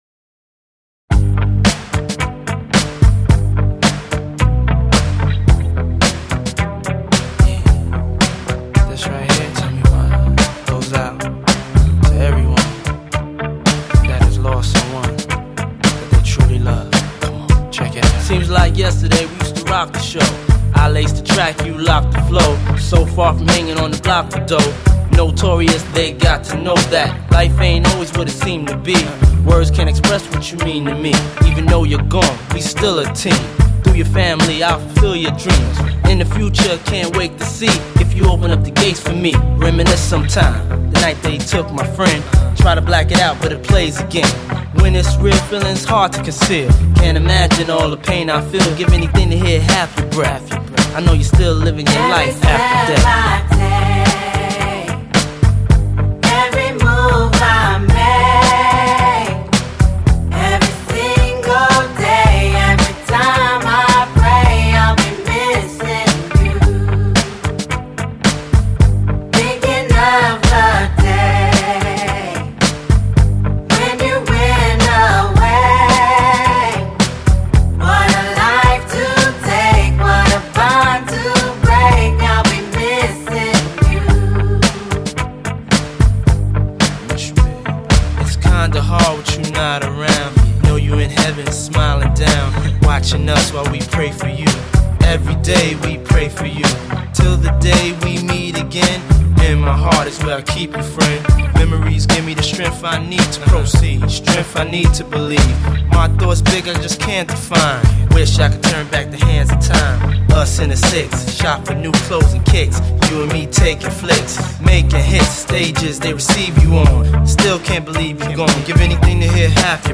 Genre: R&B, pop